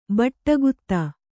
♪ baṭṭa gutta